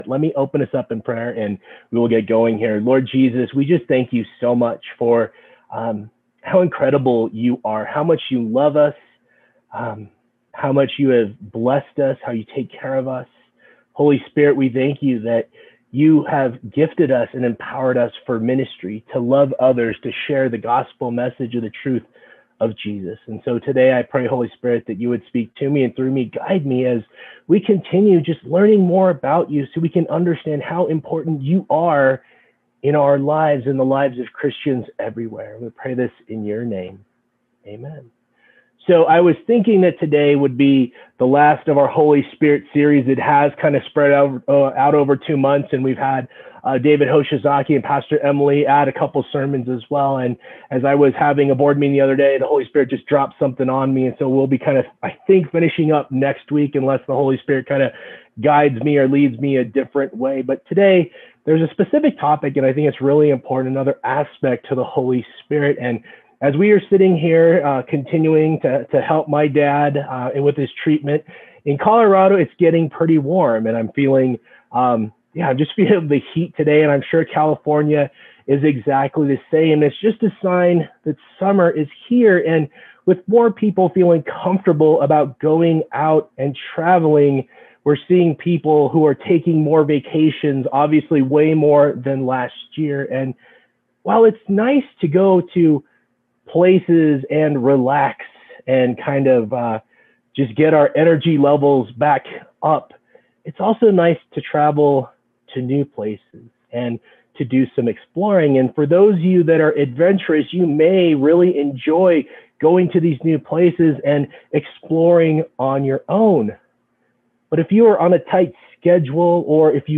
July 18, 2021 Sunday Worship Service